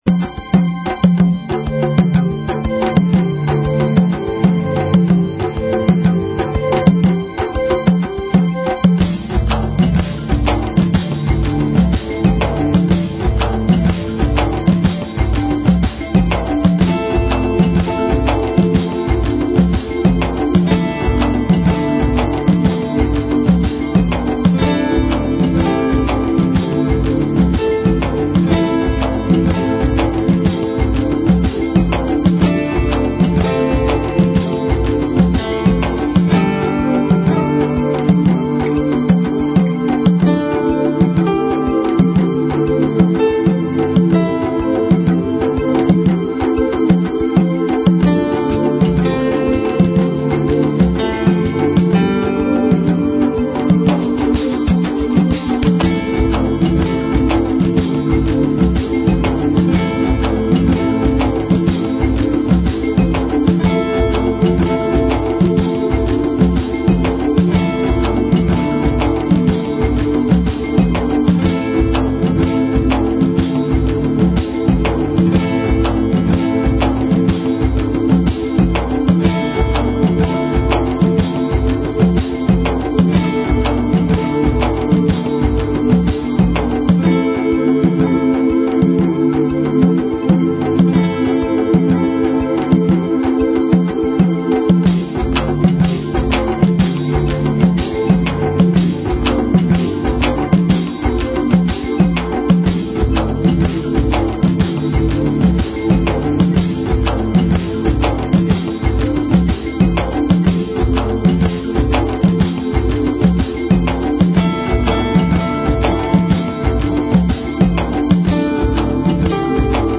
Talk Show Episode, Audio Podcast, DreamPath and Courtesy of BBS Radio on , show guests , about , categorized as
From the sublime to the ridiculous and everything in between. Comedy, music, and a continuous weaving of interviews and story telling (DreamPath style) covering numerous alternative thought topics.